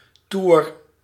Ääntäminen
IPA: /vwa.jaʒ/